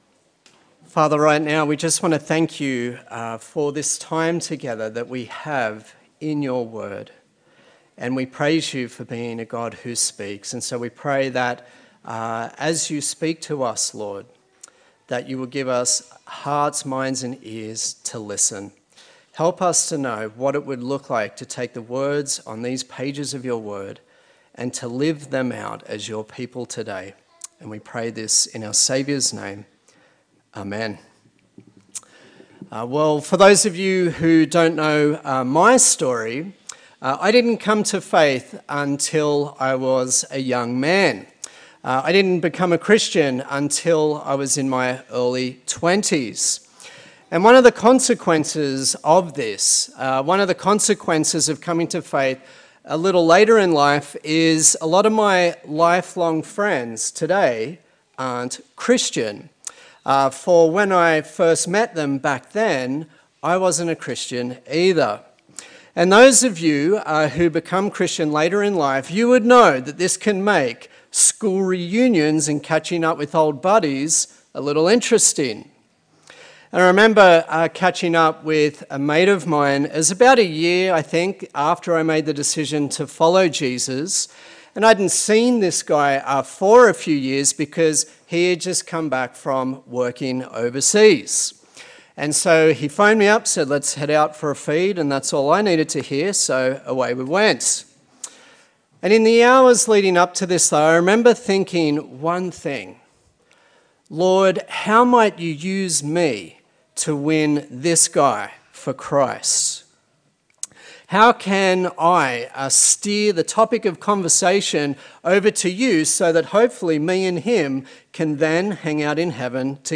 Acts Passage: Acts 25:13-26:32 Service Type: Sunday Service